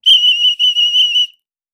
Whistle Blow Penalty.wav